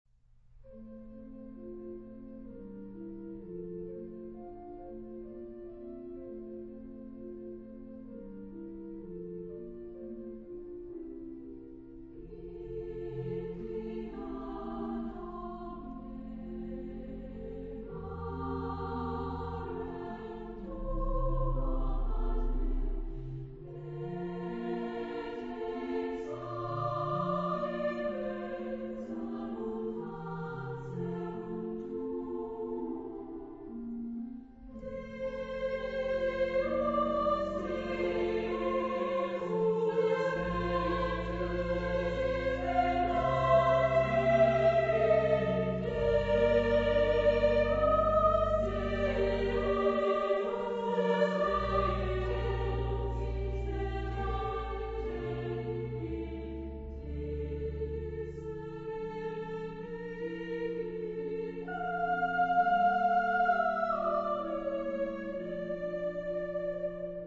Genre-Style-Form: Sacred ; Romantic ; Psalm
Mood of the piece: trusting
Type of Choir: SS  (2 women voices )
Instruments: Organ (1)
Tonality: F major